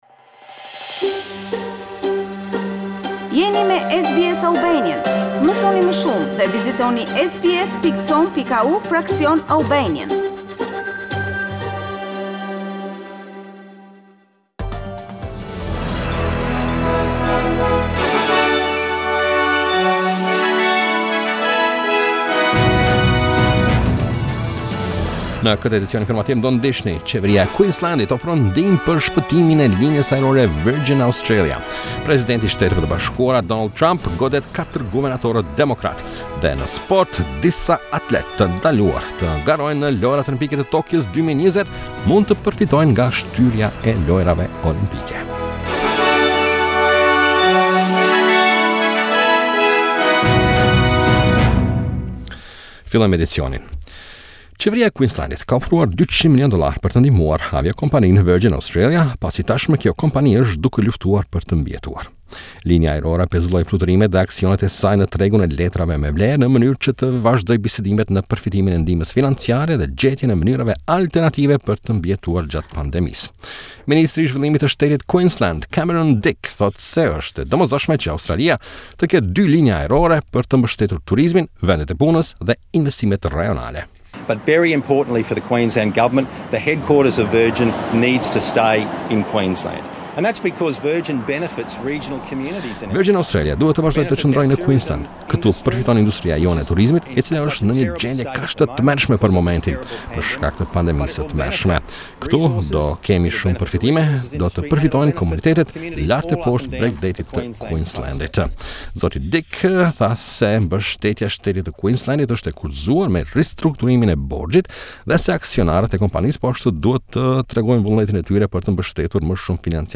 SBS News Bulletin - 18 April 2020